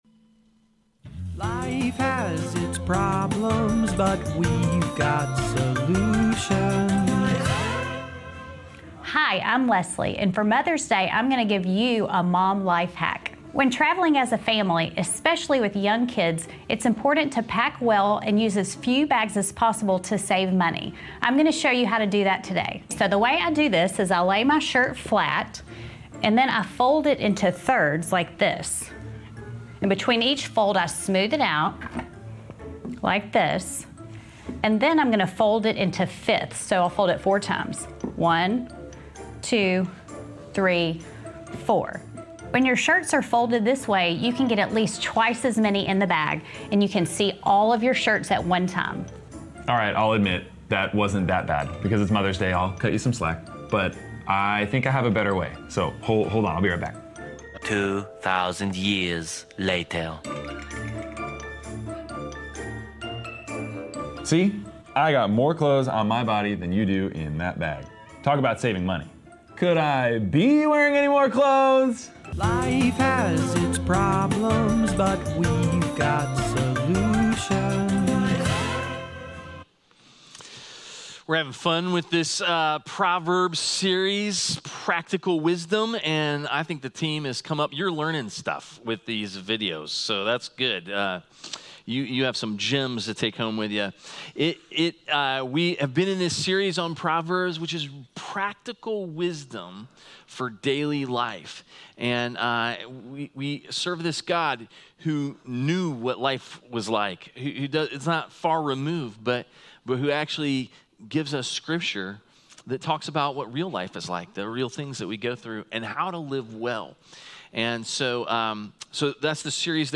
Sermons | Advent Presbyterian Church